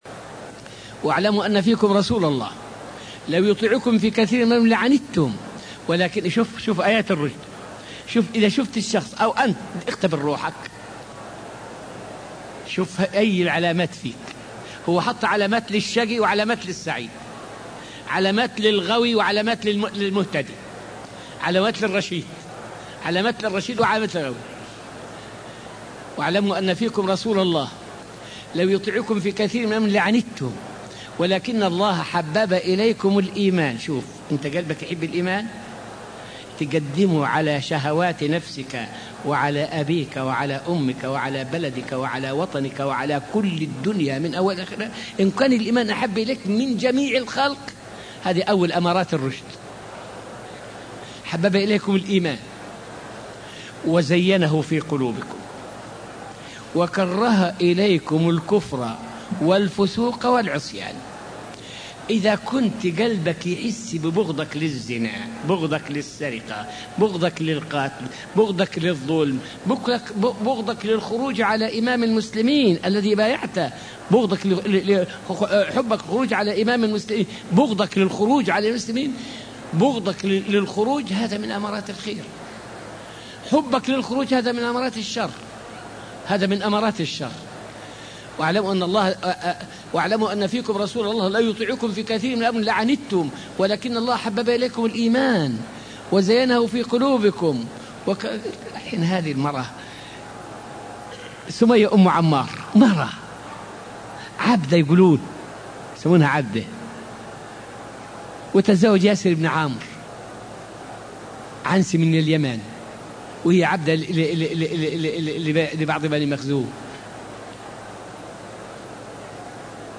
فائدة من الدرس السادس والعشرون من دروس تفسير سورة البقرة والتي ألقيت في المسجد النبوي الشريف حول محنة آل ياسر رضي الله عنهم.